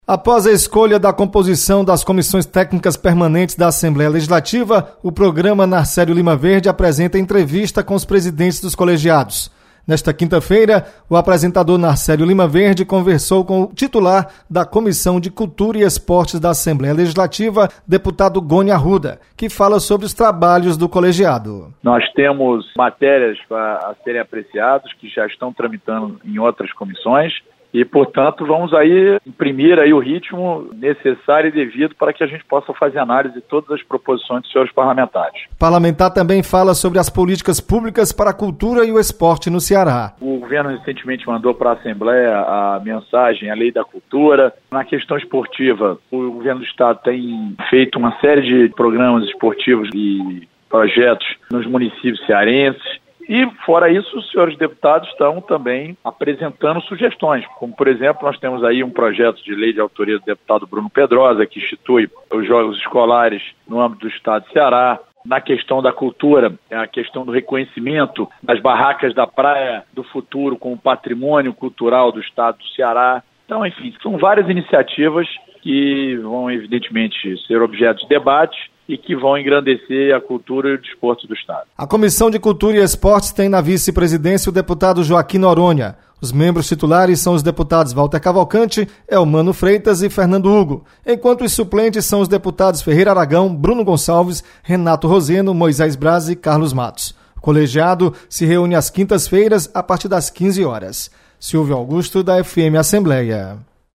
Deputado Gony Arruda destaca atividades da Comissão de Cultura e Esportes da Assembleia.